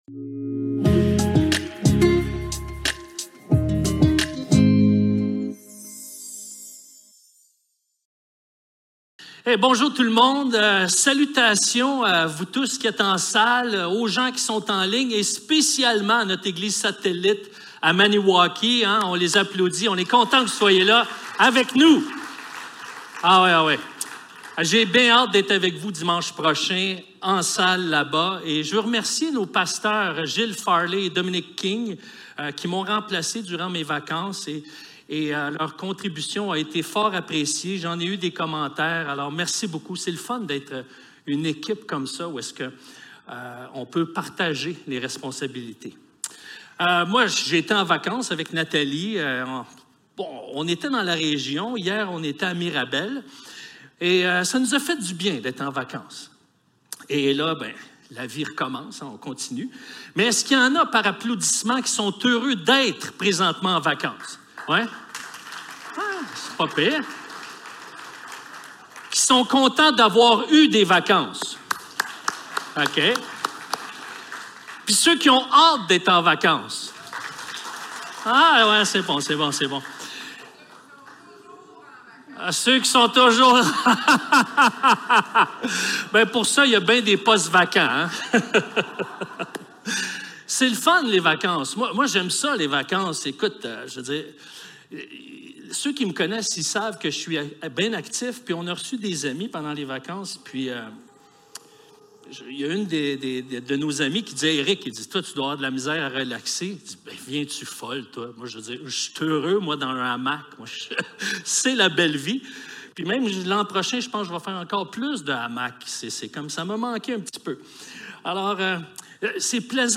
Actes 24 Service Type: Célébration dimanche matin Envoyés #36 L'apôtre Paul est faussement accusé par des gens qui ont peur de son influence.